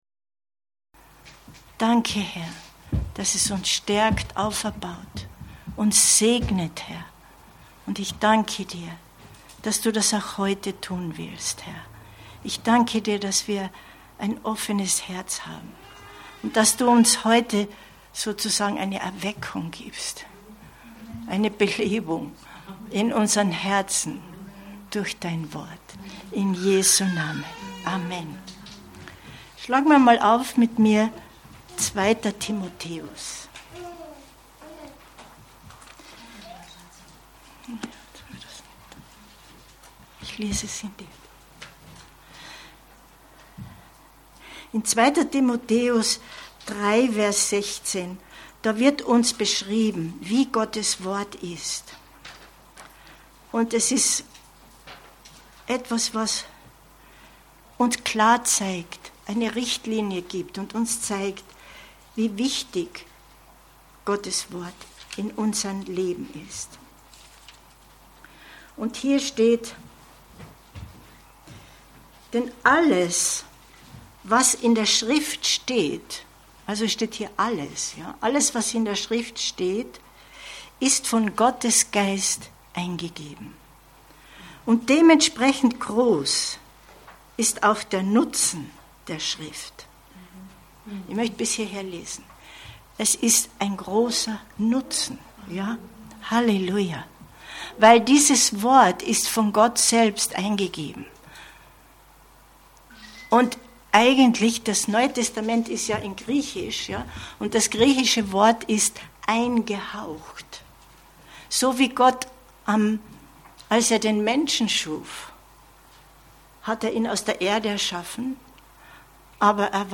Info Info Gottes Wort hören und verstehen 01.10.2023 Predigt herunterladen